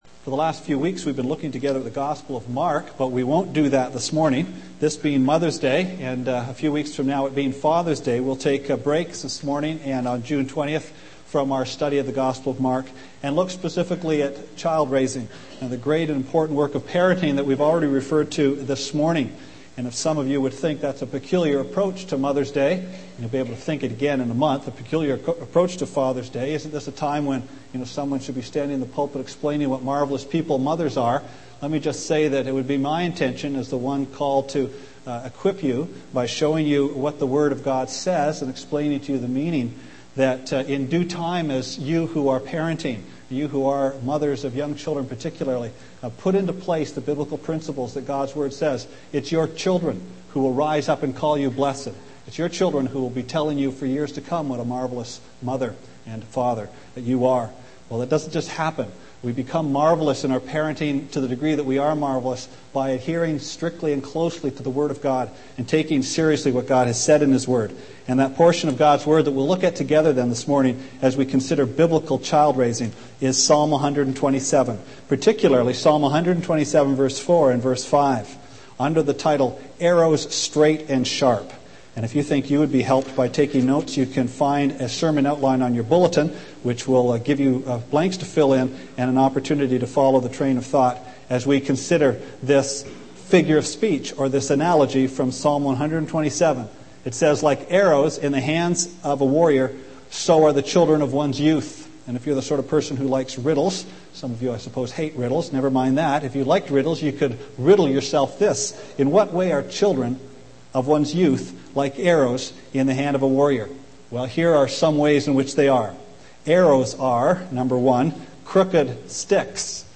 Sermon Archives - West London Alliance Church